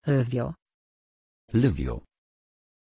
Aussprache von Lyvio
pronunciation-lyvio-pl.mp3